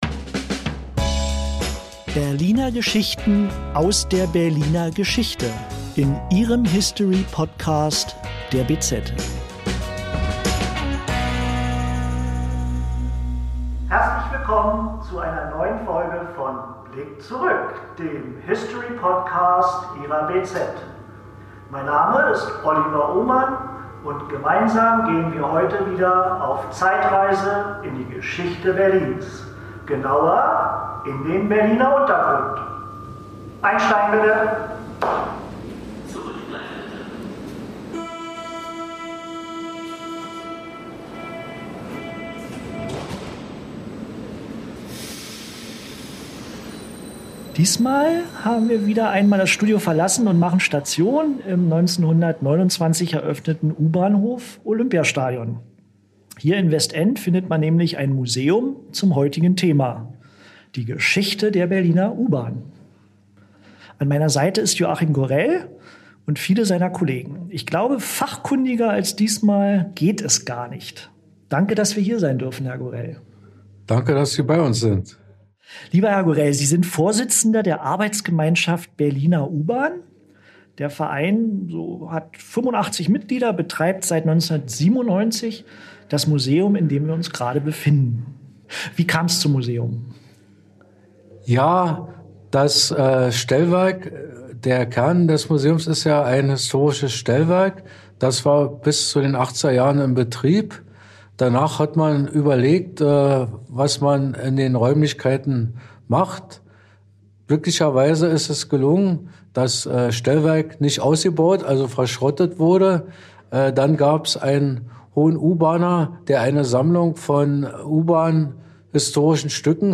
Mit seinen Kollegen betreibt er das tolle U-Bahnmuseum am Olympiastadion, in dem der neue Podcast aufgezeichnet wurde.